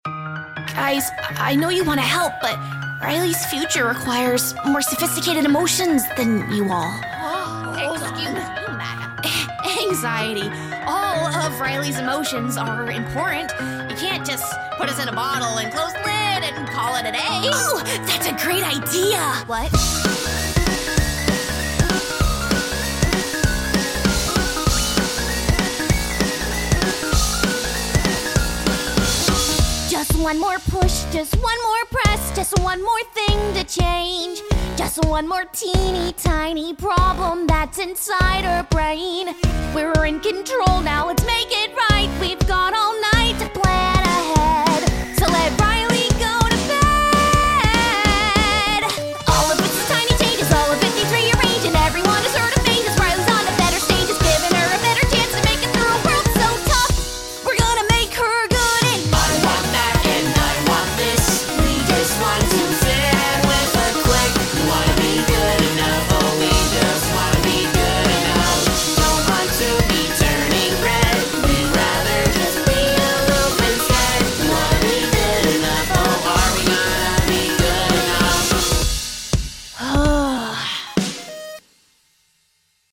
nerdcore song